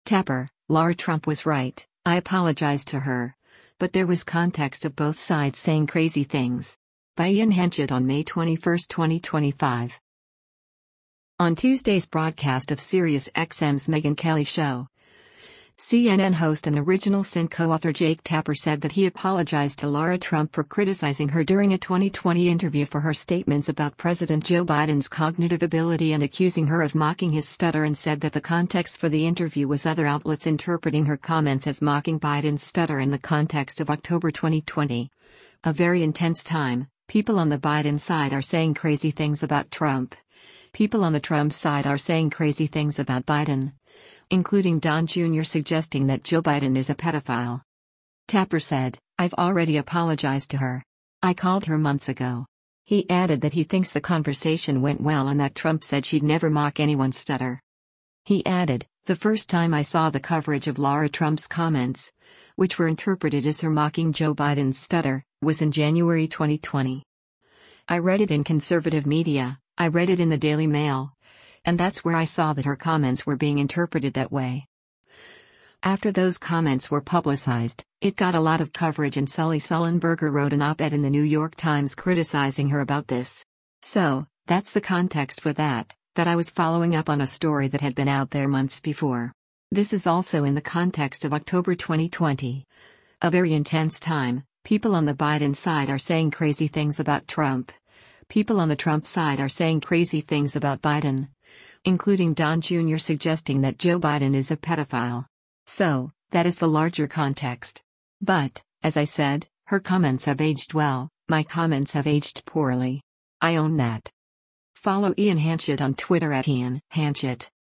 On Tuesday’s broadcast of SiriusXM’s “Megyn Kelly Show,” CNN host and “Original Sin” co-author Jake Tapper said that he apologized to Lara Trump for criticizing her during a 2020 interview for her statements about President Joe Biden’s cognitive ability and accusing her of mocking his stutter and said that the context for the interview was other outlets interpreting her comments as mocking Biden’s stutter and “the context of October 2020, a very intense time, people on the Biden side are saying crazy things about Trump, people on the Trump side are saying crazy things about Biden, including Don Jr. suggesting that Joe Biden is a pedophile.”